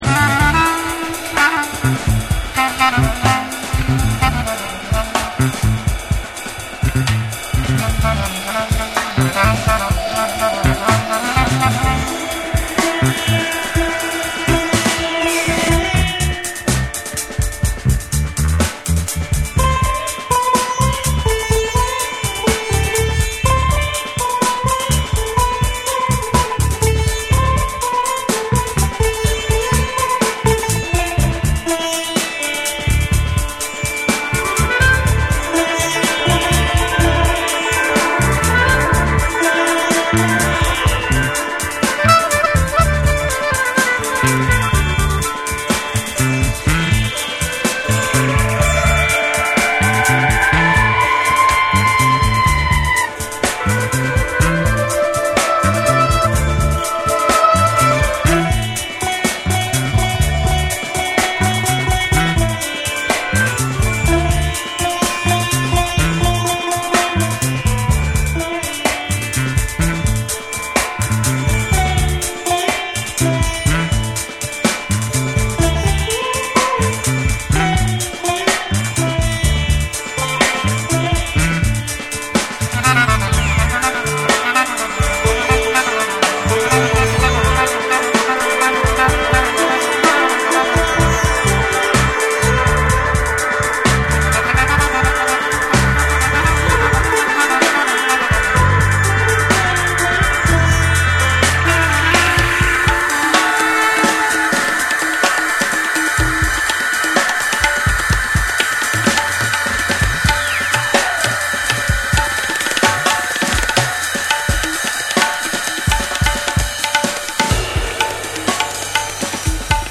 インド古典音楽の神秘性と、ジャズ、サイケ、ダブどが交錯する至高のクロスカルチャー・グルーヴ！
SOUL & FUNK & JAZZ & etc / WORLD